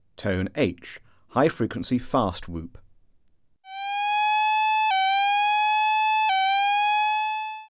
Alert Tone: H